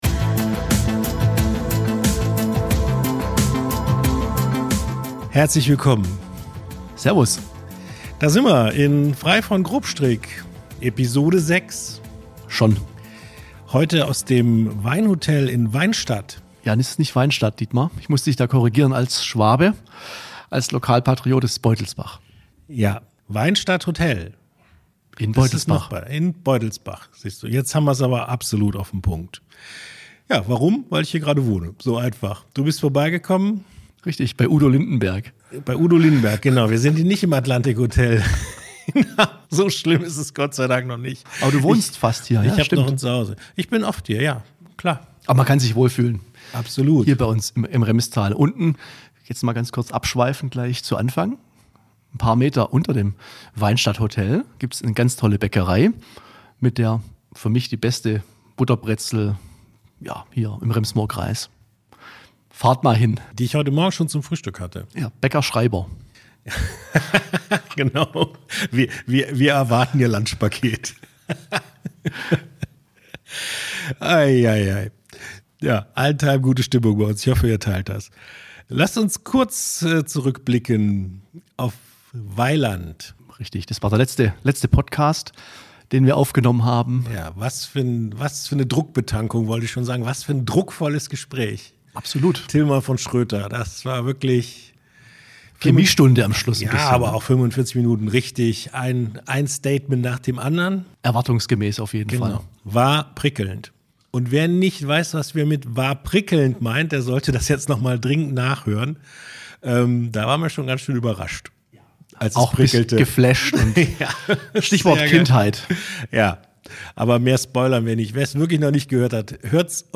Locker bis launig, meinungsstark und informativ, das ist „Frei von Grobstrick“, der HeizungsJournal-Podcast.